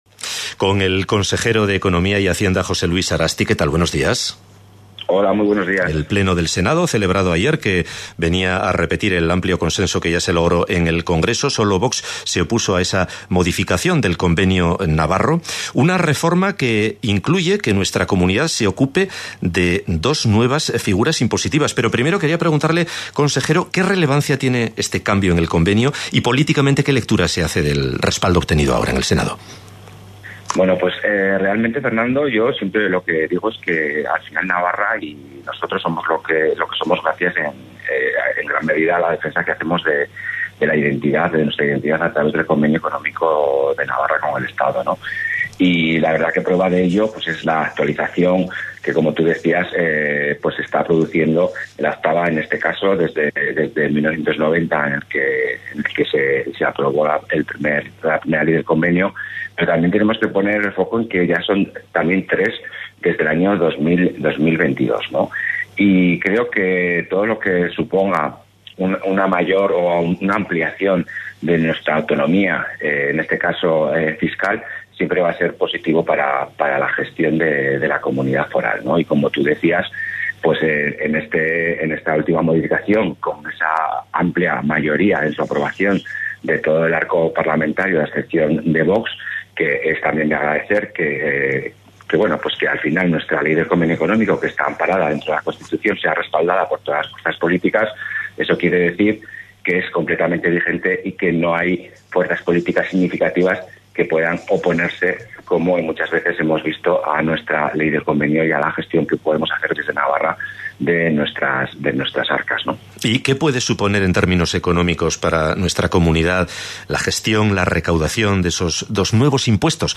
La Primera llamada del día: José Luis Arasti, consejero de Economía